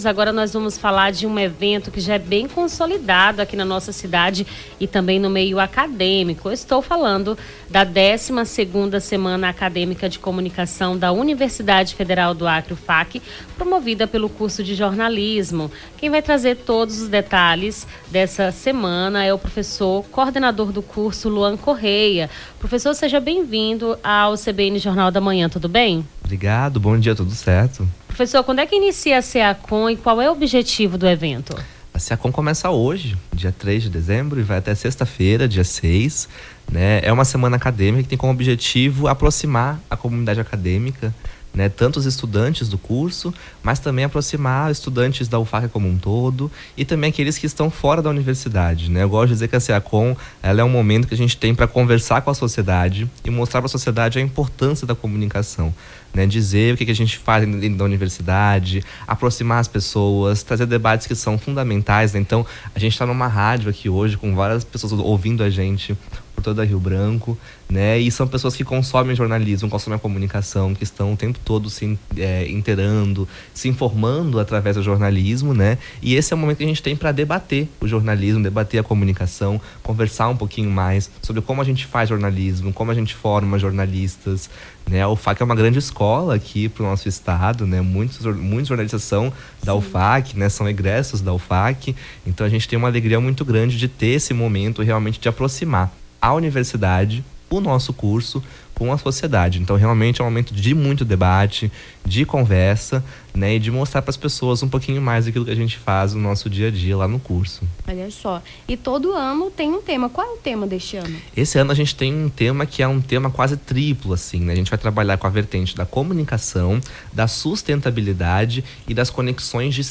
Nome do Artista - CENSURA - ENTREVISTA SACOM UFAC (03-12-24).mp3